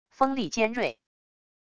锋利尖锐wav音频